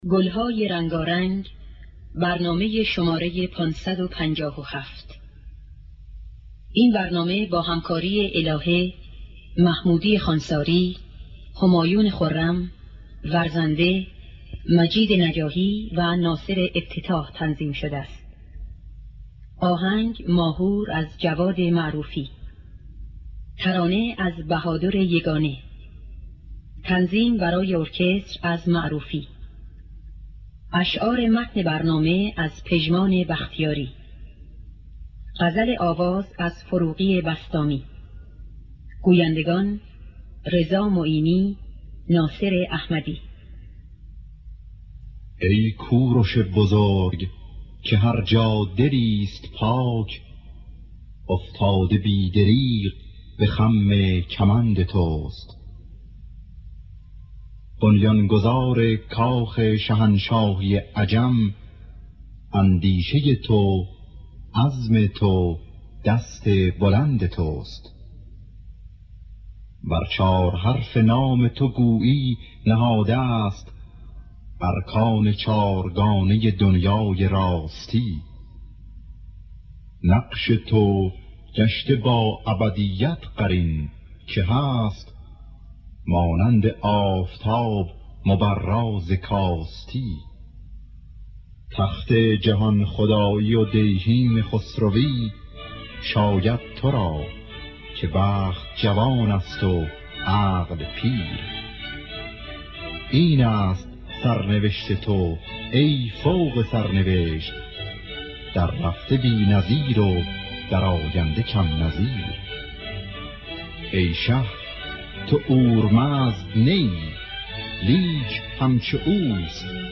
در دستگاه ماهور